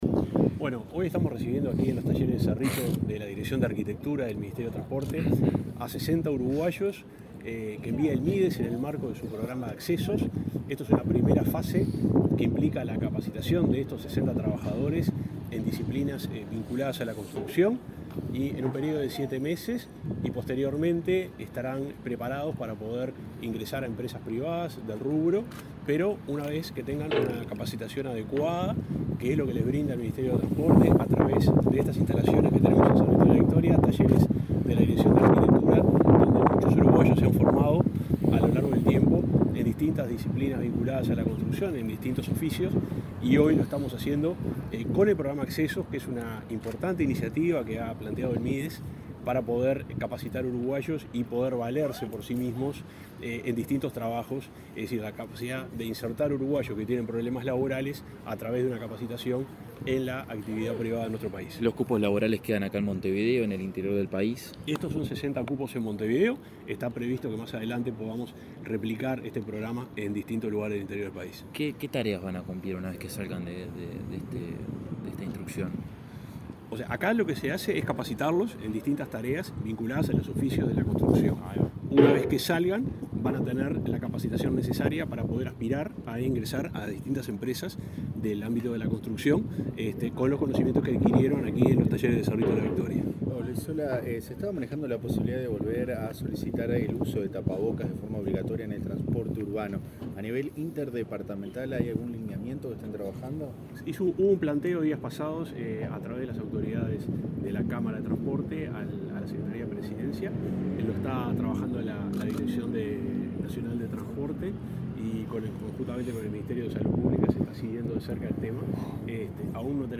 Declaraciones a la prensa del subsecretario de Transporte, Juan José Olaizola
El titular del Ministerio de Desarrollo Social (Mides), Martín Lema, y el subsecretario de Transporte, Juan José Olaizola, recibieron este martes 31 a 60 participantes del programa Accesos, del Mides, que serán capacitados en los Talleres Cerrito, de la segunda cartera mencionada, en el marco de un programa socioeducativo y laboral. Antes del acto, Olaizola dialogó con la prensa.